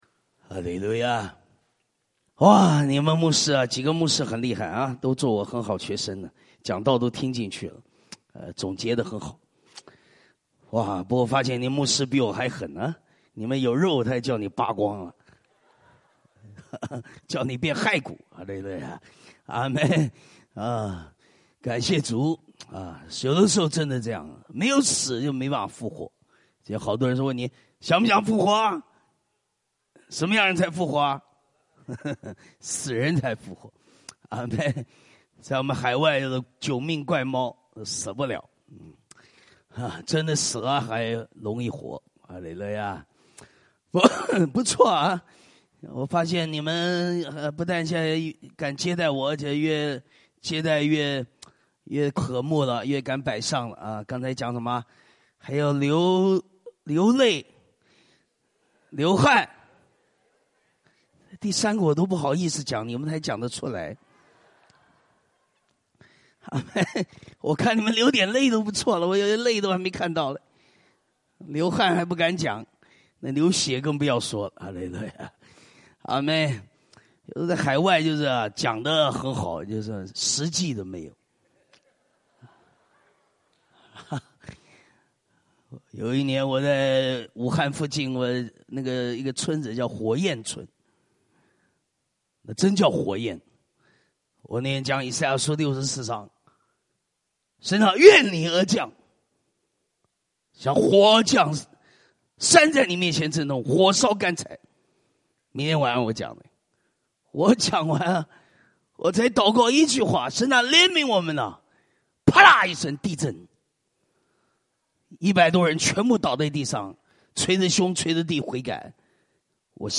《圣灵与复兴》特会三 — 春雨澆灌（2016-09-17）